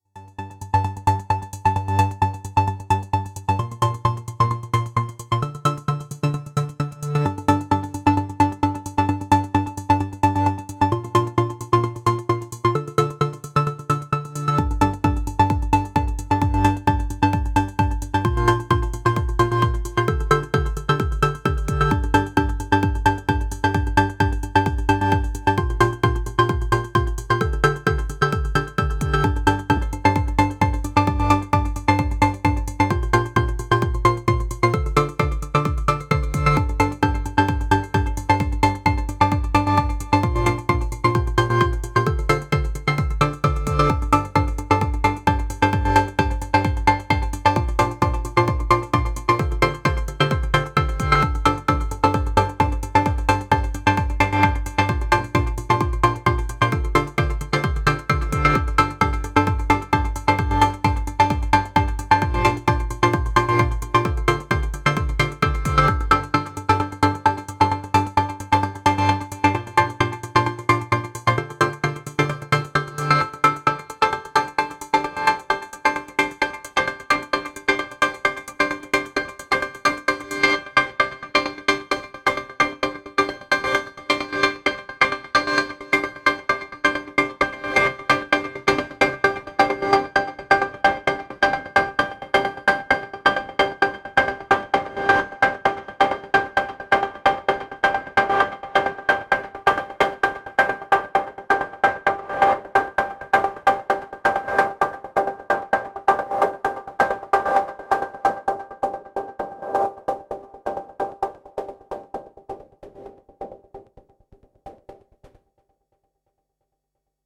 This is a Subharmonicon providing the main sound source, going through a Strega into the Dynamics circuit of an 0-coast to provide the low-pass gate type sound, drums and FX from an A4. One of the elements of Barker’s sound is a sort of “grainy” quality, which I think I finally got here by using the Strega to add quite a lot of noisy artifacts to the clean sound coming from the SubH.